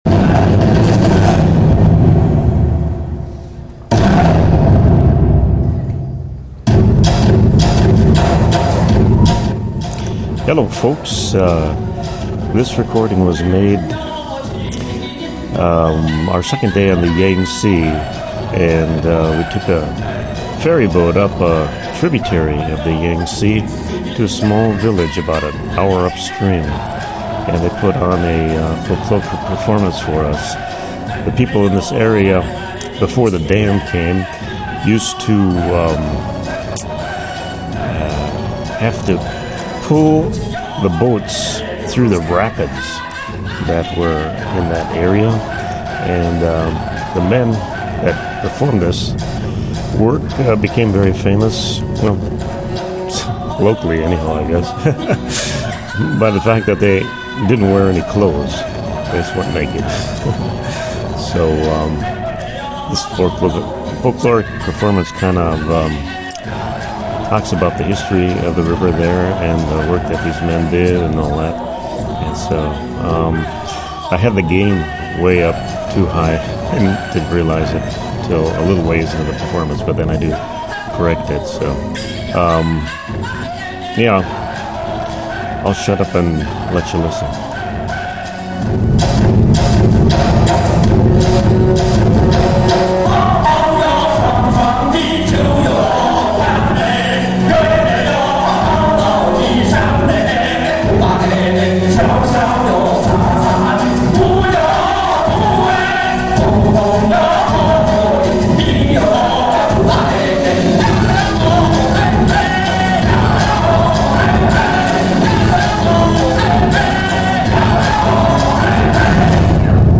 A Minority Folkloric Performance by the Tujia People